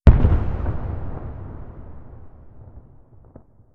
Boom!